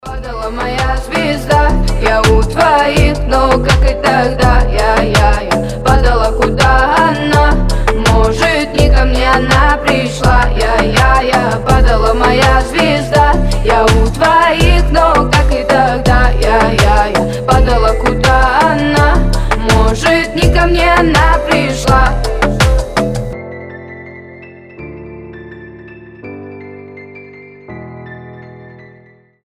• Качество: 320, Stereo
женский голос
спокойные